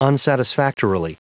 Prononciation du mot unsatisfactorily en anglais (fichier audio)
Prononciation du mot : unsatisfactorily
unsatisfactorily.wav